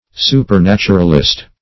supernaturalist - definition of supernaturalist - synonyms, pronunciation, spelling from Free Dictionary
Supernaturalist \Su`per*nat"u*ral*ist\, n.